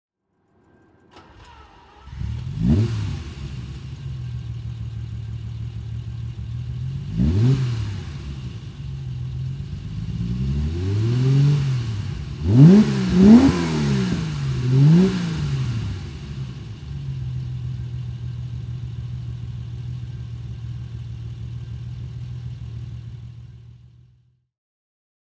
Ferrari 365 GT 2+2 (1970) - Starten und Leerlauf